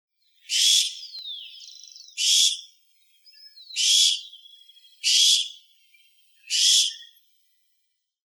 Owl, Barn
Tyto alba
Owl_Barn.mp3